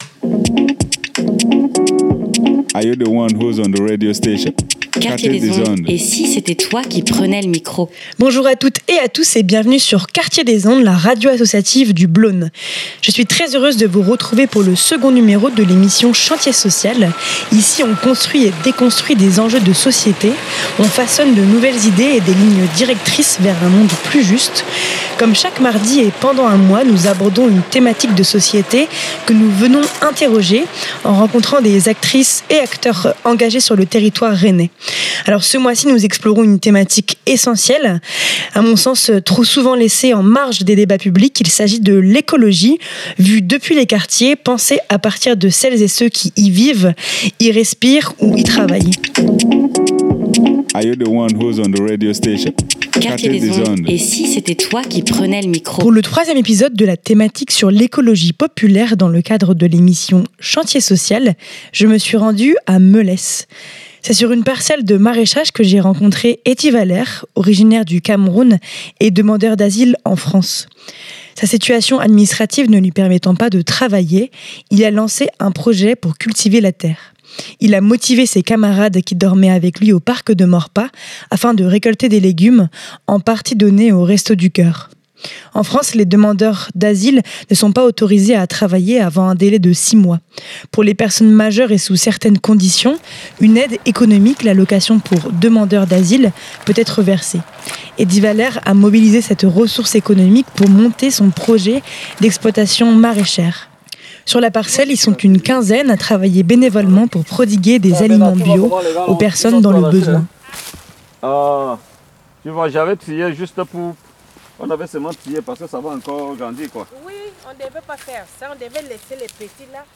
Pour le troisième épisode de la thématique sur l’écologie populaire dans le cadre de l’émission chantier social, je me suis rendue à Melesse.